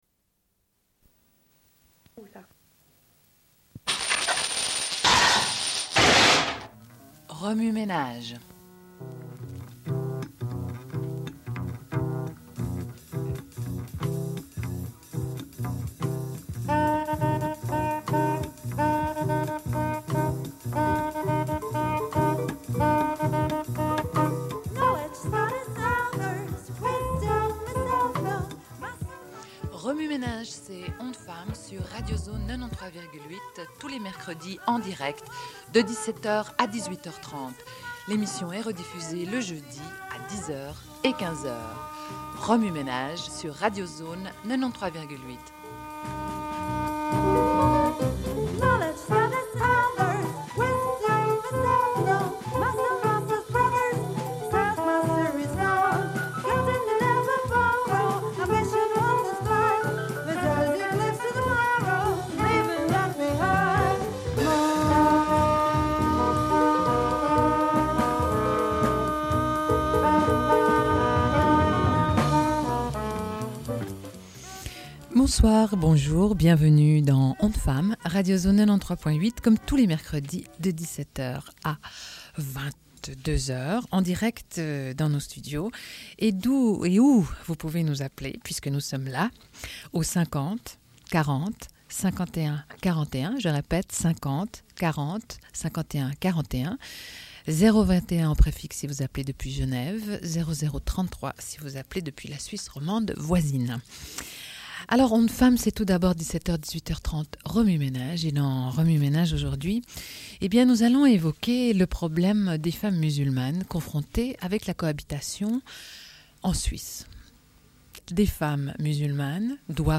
Une cassette audio, face A31:20